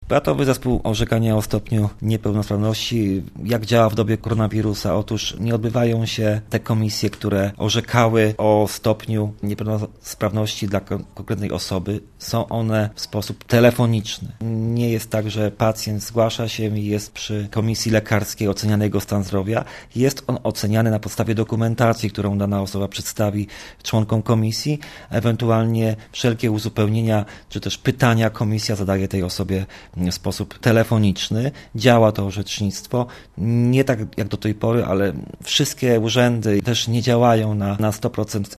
Mówi starosta sandomierski Marcin Piwnik: